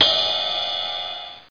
MetalPing.mp3